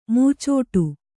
♪ mūcōṭu